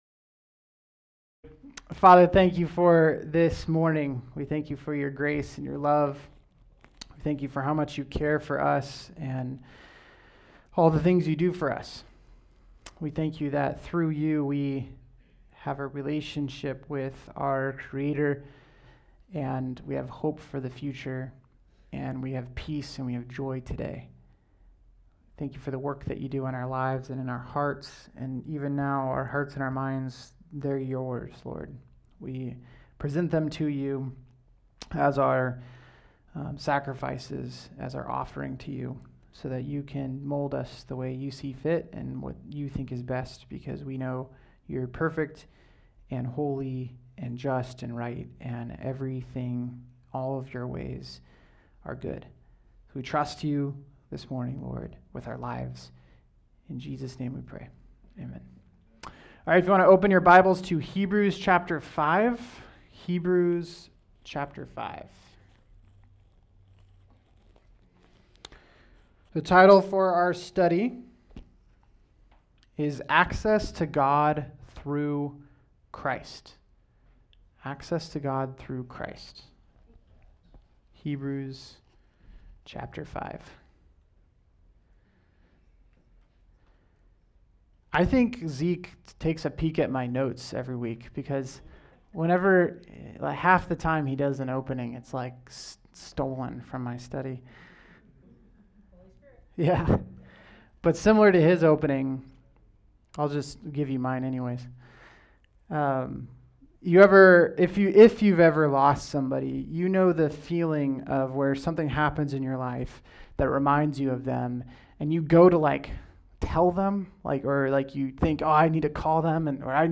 All Sermons
the Greatest Topic: Sunday Morning 2025 Book